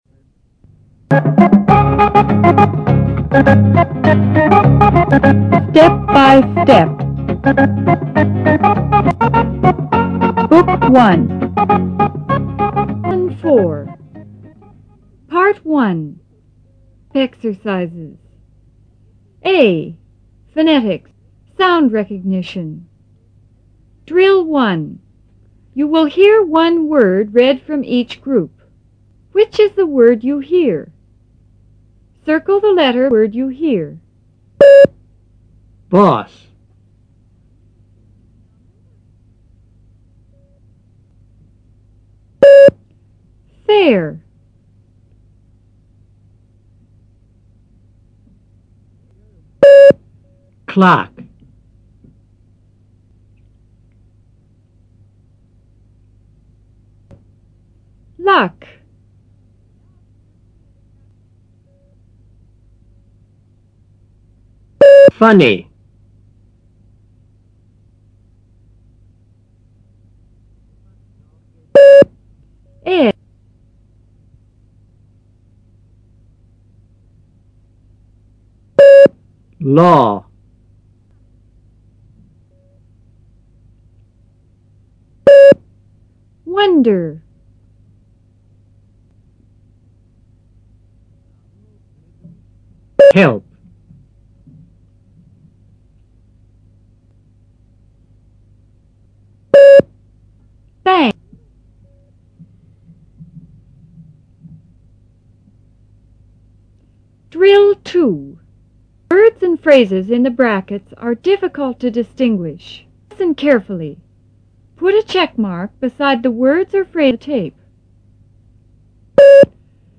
A．Phonetics: Sound Recognition
Directions:  You will hear one word read from each group.